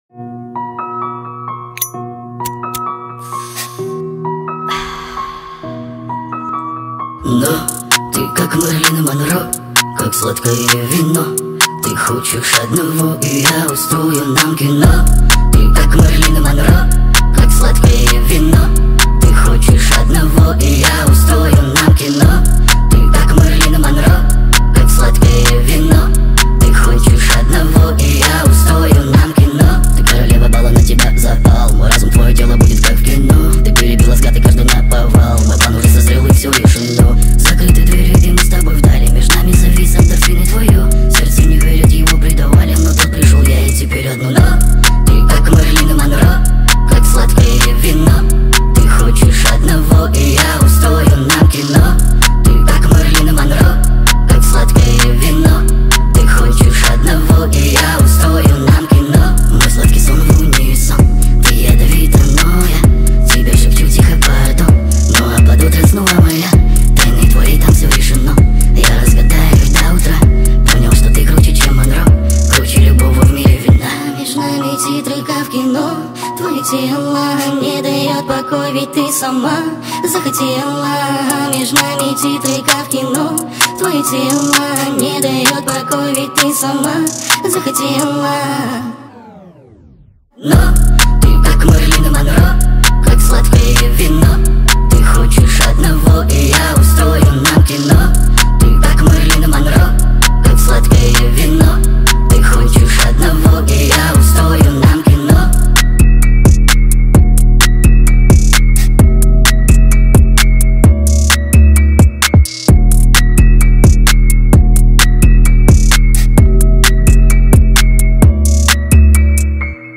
Speed Up TikTok Remix